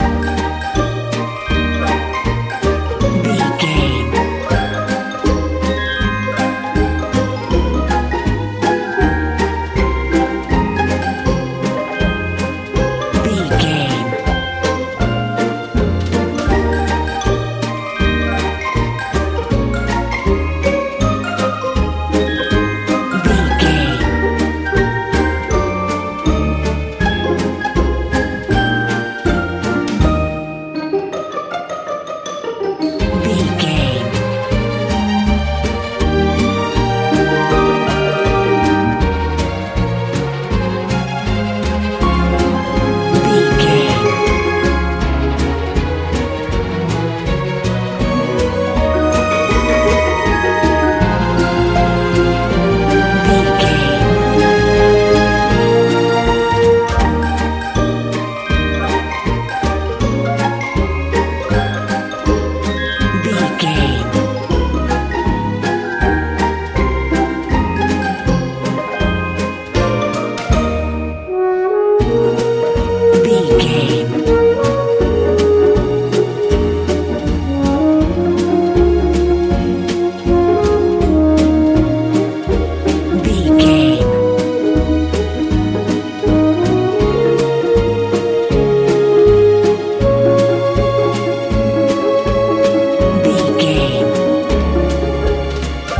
Ionian/Major
D♯
cheerful/happy
bright
playful